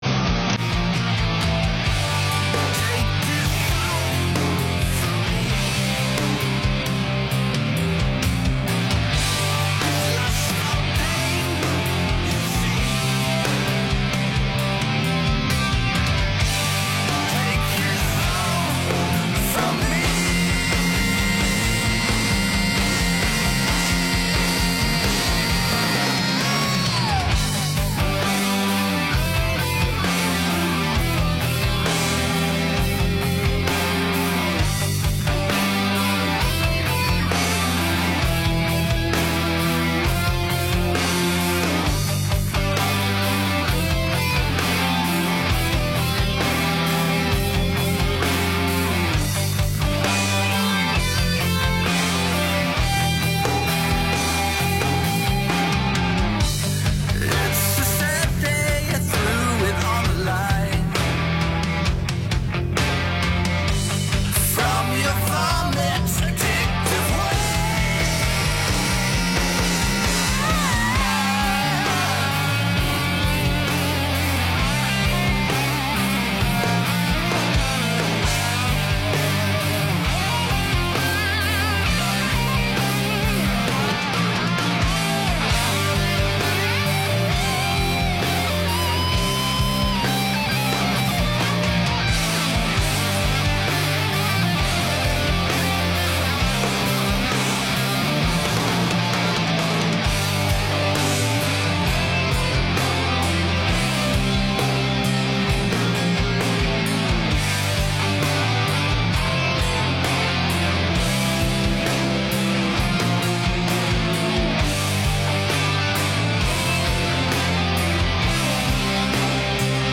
Tonight we check in with the guys from Rurally Bankrupt, as they make their way to the studio to give you one live, a debut, and more.